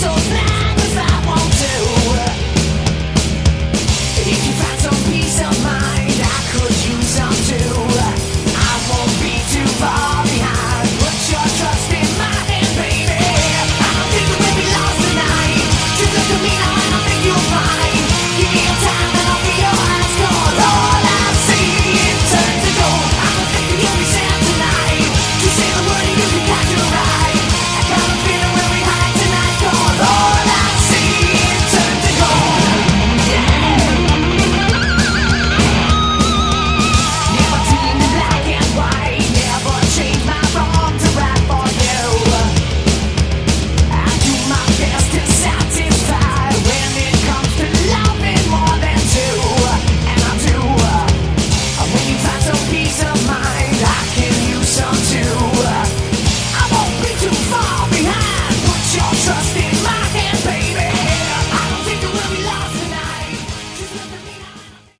Category: Glam
vocals
guitars
bass
drums